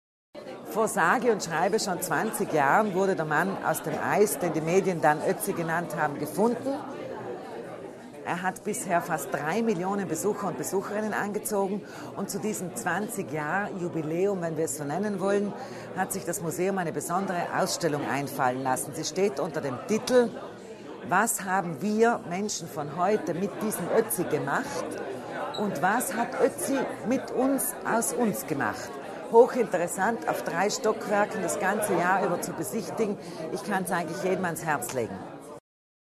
Landesrätin Kasslatter Mur über die wichtigsten Neuheiten